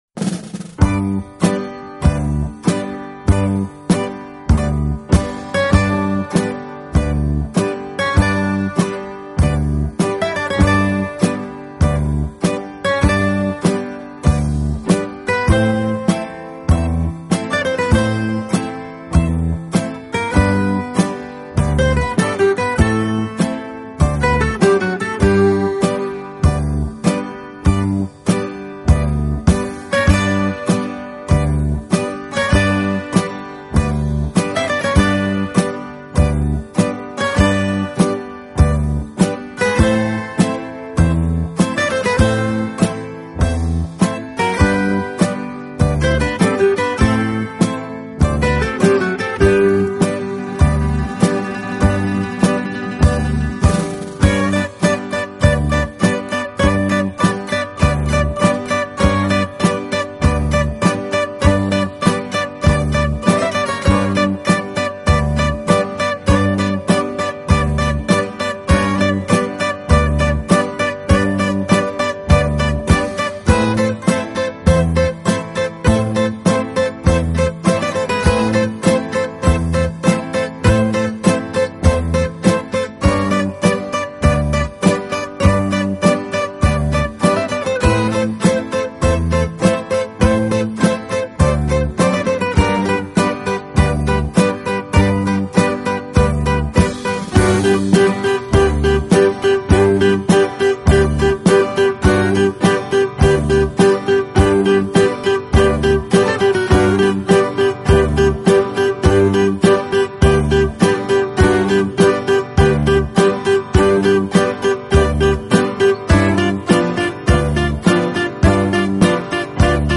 音乐风格：Instrumental, Acoustic Guitar